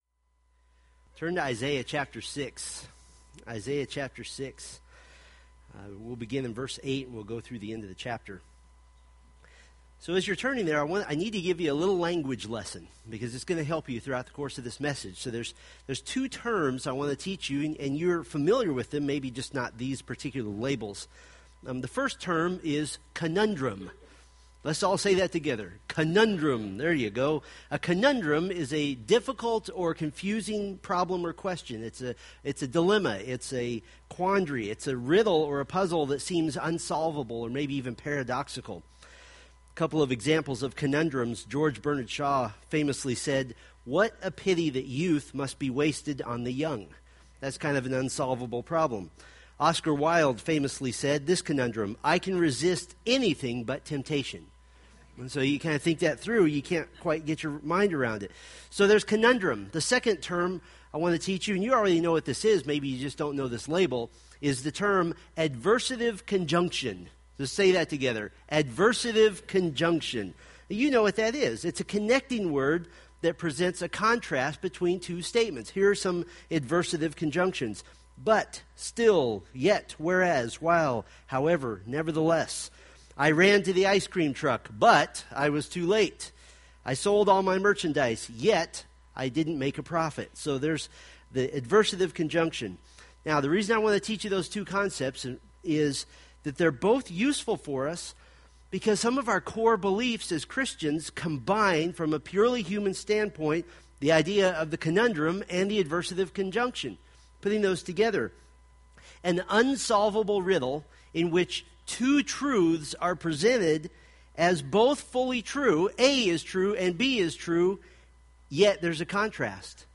Preached November 22, 2015 from Isaiah 6:8-13